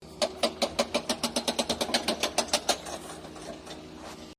Jackhammer Start and Fail
SFX
yt_TsiIuIobRDI_jackhammer_start_and_fail.mp3